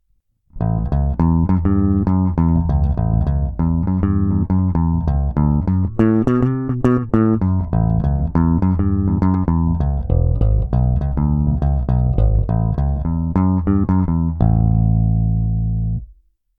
Omlouvám se za občasné přeznívající struny, trsátko není zrovna moje parketa a mám trochu problém s tlumením, tak mi to odpusťte, spotřeboval jsem na to i tak snad sto pokusů.
Nahrávka Rock'n'Roll - hráno trsátkem - snímače v zapojení humbucker:
krkový snímač